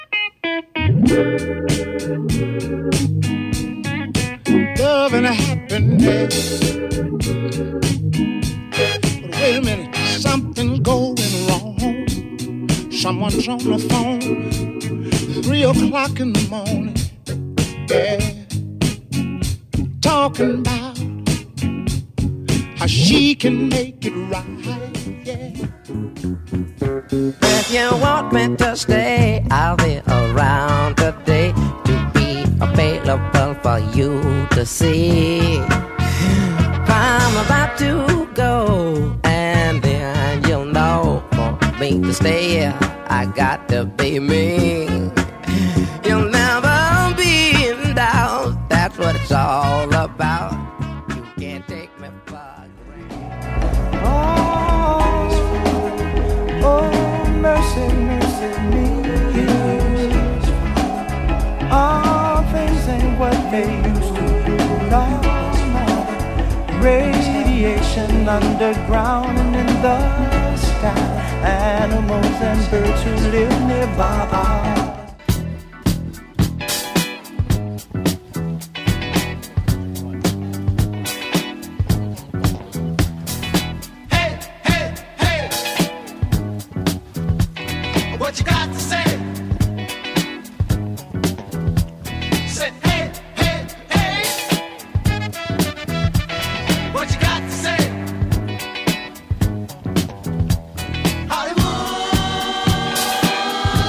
Mix of Funk and Soul Hits for All Day